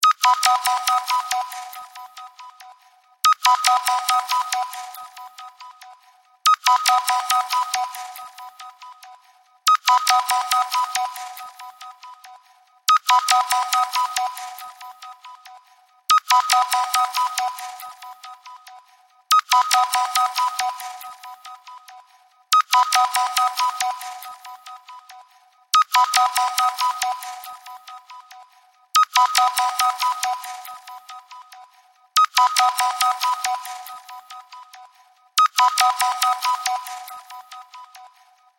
明るく爽快な雰囲気の中で、楽しい瞬間を演出する着信音です。
このループの着信音は、快活なメロディと柔らかなリズムが明るさと希望をもたらします。